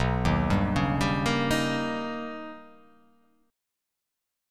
B+M9 chord